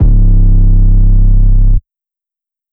808 (Diamonds All On My Wrist).wav